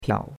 piao3.mp3